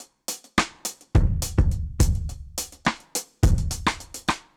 Index of /musicradar/dub-drums-samples/105bpm
Db_DrumsB_Dry_105-03.wav